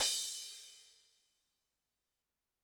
Drums_K4(17).wav